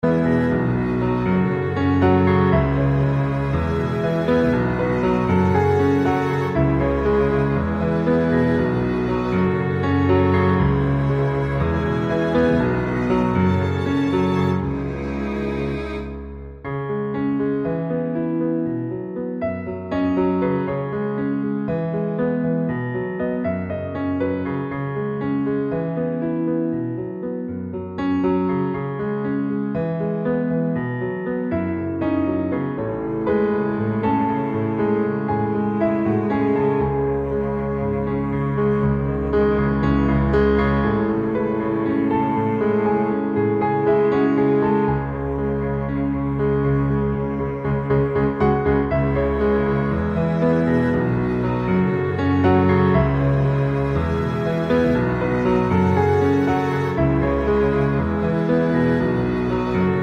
Original Key with Shorter Instrumental